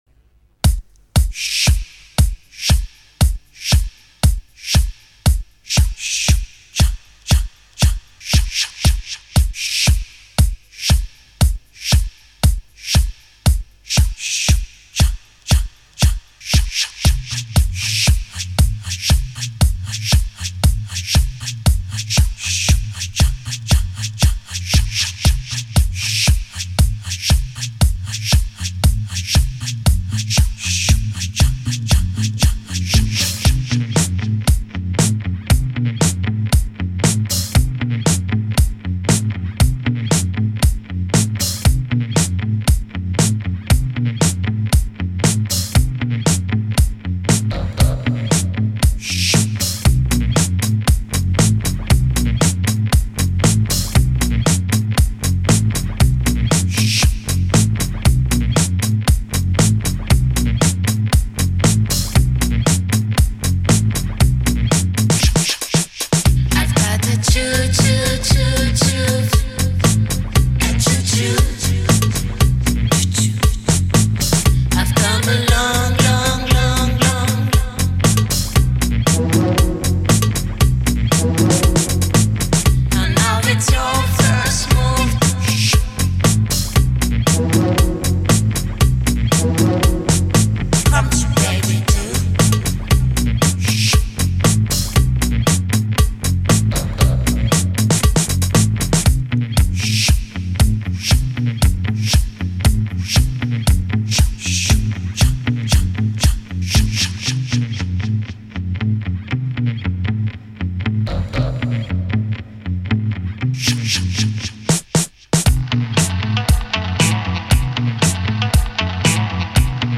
Y suena a eso, a algo completamente vivo y un tanto salvaje